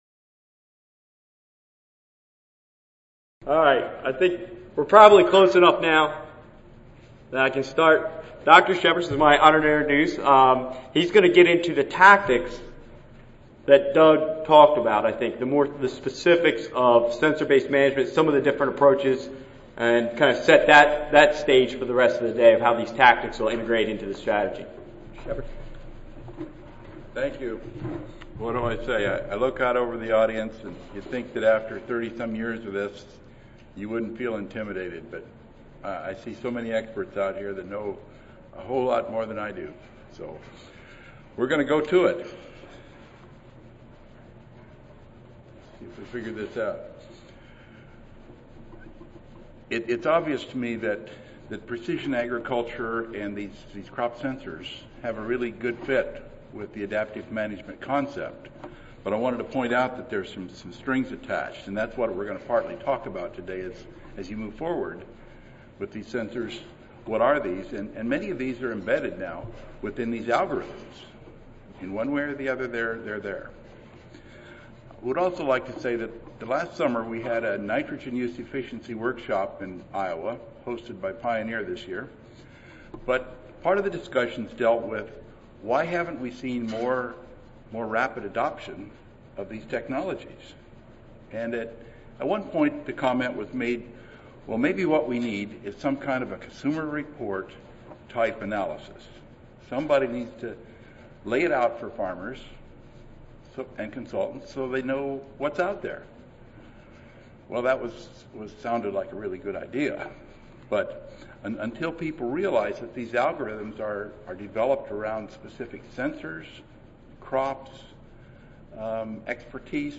USDA-ARS Recorded Presentation Audio File 9:35 AM 319-4 On-Farm Evaluation Tools and Approaches to Quantify Risks in Nitrogen Management.